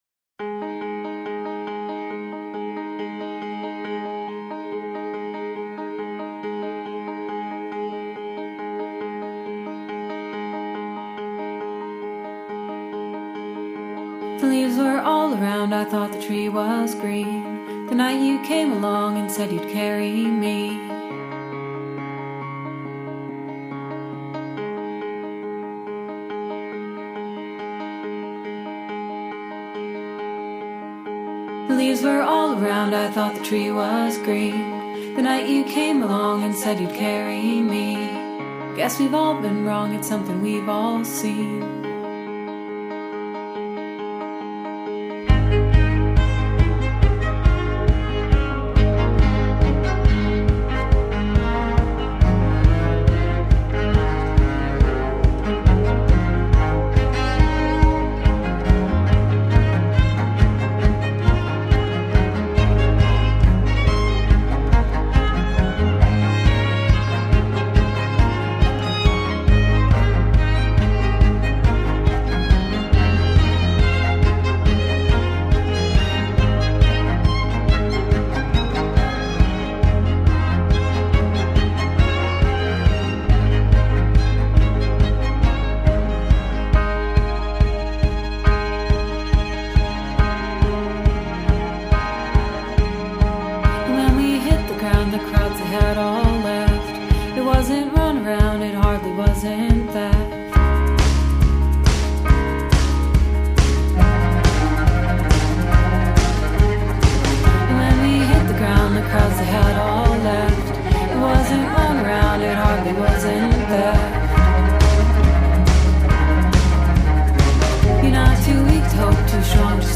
orchestral indie folk single